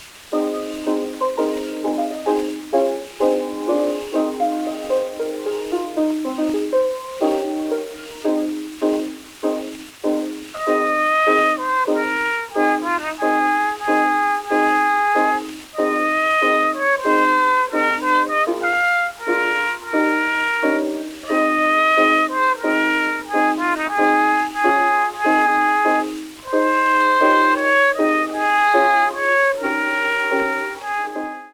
CORNET
original double-sided recordings made 1900-1922